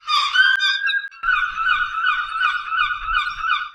Seagull 004.wav